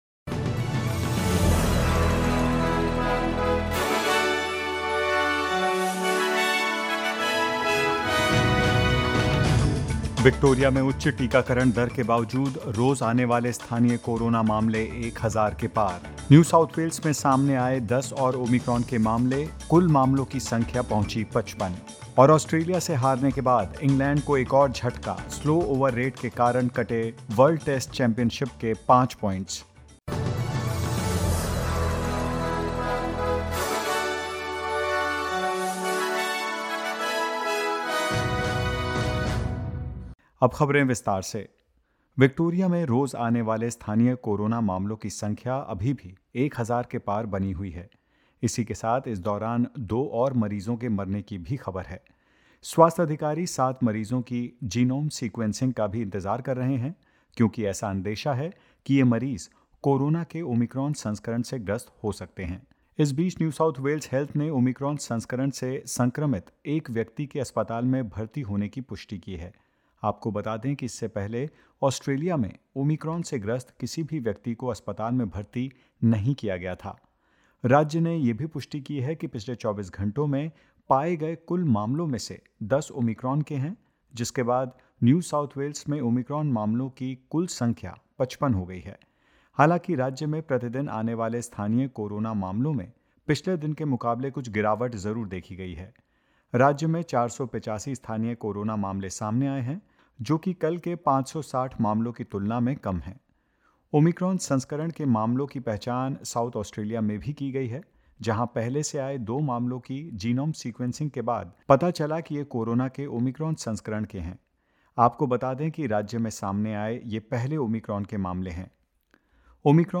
In this latest SBS Hindi news bulletin of Australia and India: Omicron variant of COVID-19 in New South Wales grows to 55; COVID infections in Victoria above 1000 for the seventh day in a row and more.